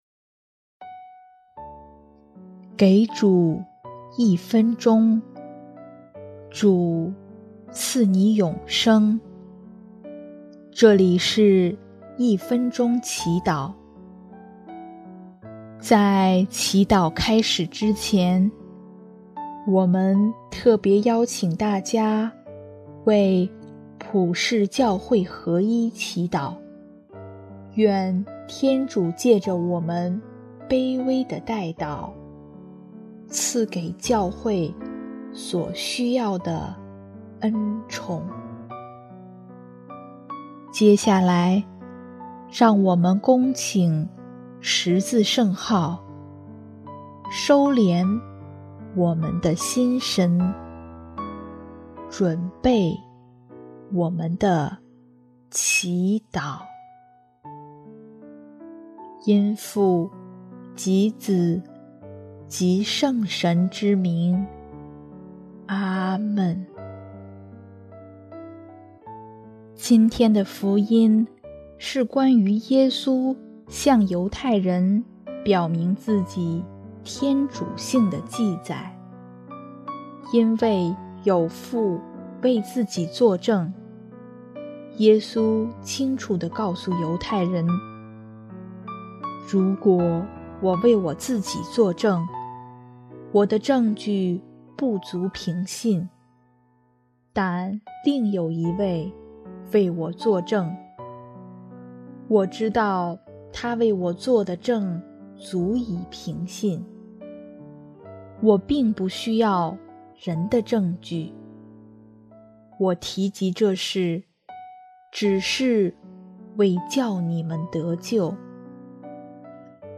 音乐：第二届华语圣歌大赛参赛歌曲《在天大父》（普世教会合一）